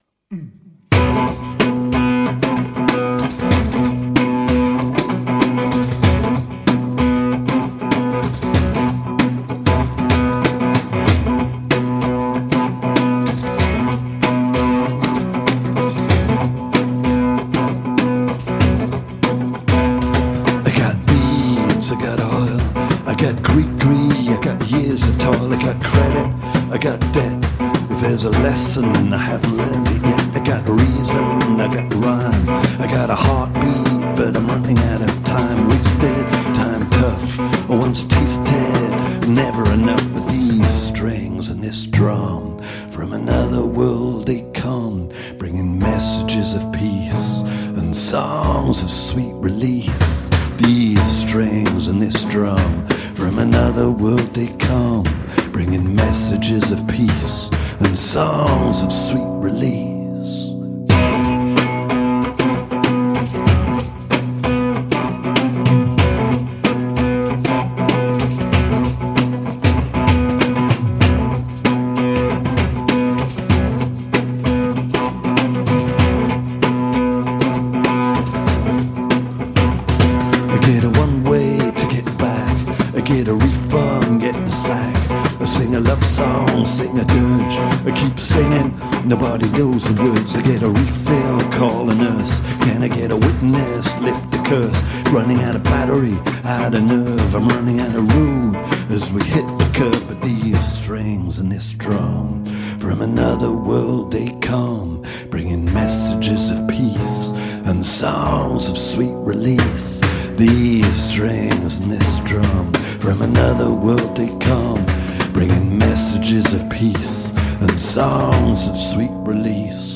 intervista
ci regalano tre brani dal vivo